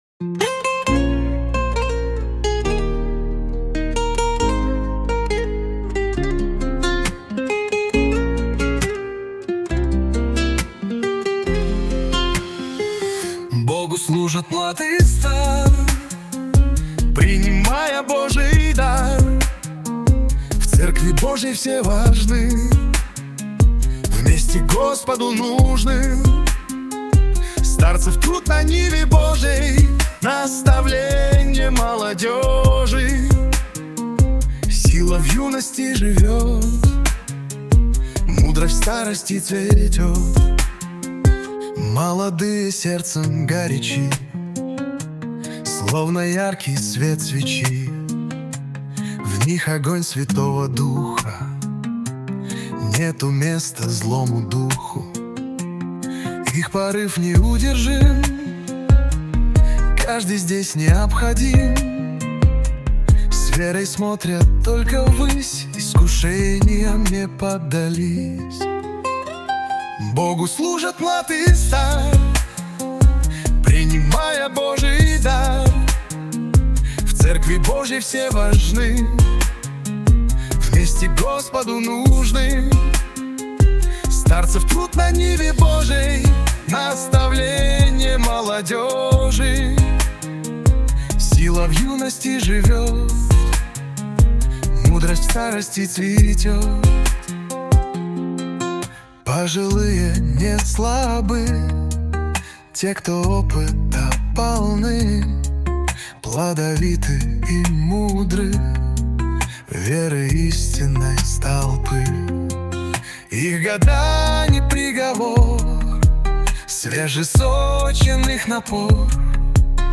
песня ai
201 просмотр 1009 прослушиваний 44 скачивания BPM: 68